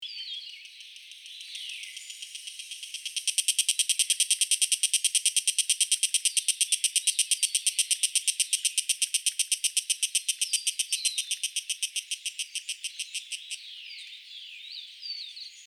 11-3小暮蟬2011溪頭1.mp3
小暮蟬 Tanna viridis
南投縣 鹿谷鄉 溪頭
16-18 錄音環境 森林 發聲個體 行為描述 雄蟬呼喚歌聲 錄音器材 錄音: 廠牌 Denon Portable IC Recorder 型號 DN-F20R 收音: 廠牌 Sennheiser 型號 ME 67 標籤/關鍵字 小暮蟬 備註說明 MP3檔案 11-3小暮蟬2011溪頭1.mp3